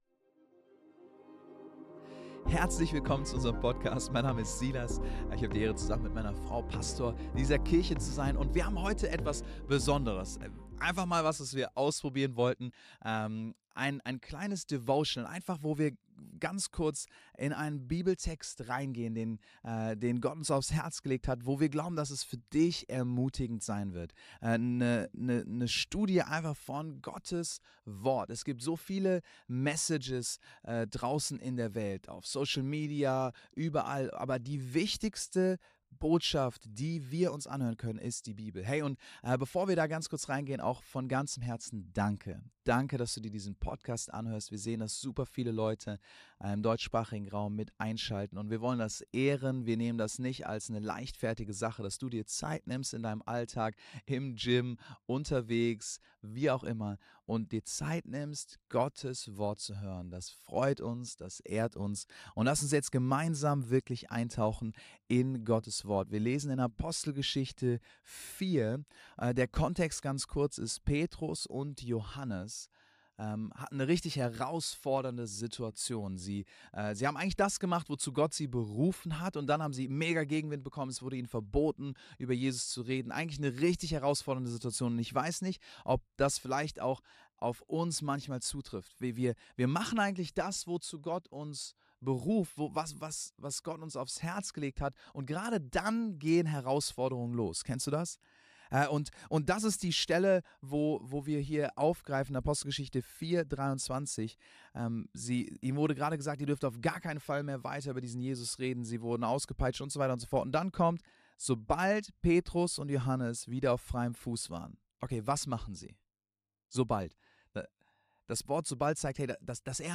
kurze Andacht